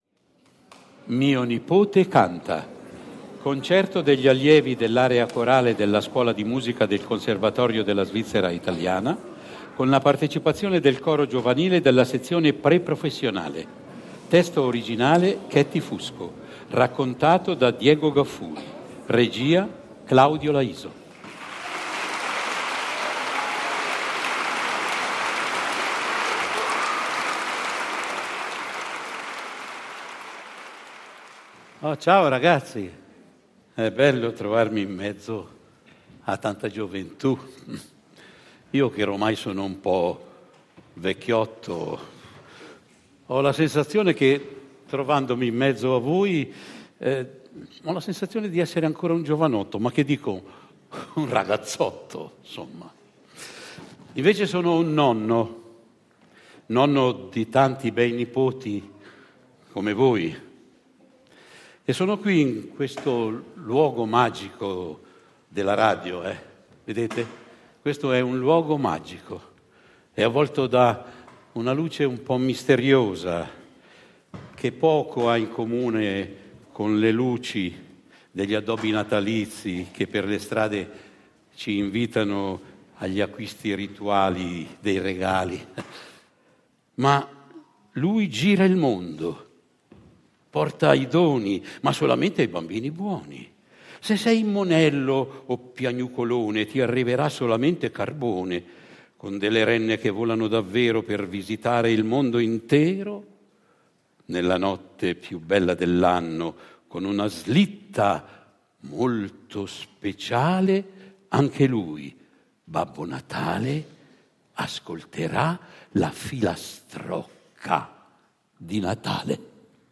i meravigliosi canti natalizi
Mio nipote canta evento pubblico realizzato dal Conservatorio della Svizzera italiana in collaborazione con Rete Due, è stato registrato all’ Auditorio Stelio Molo domenica 20 dicembre.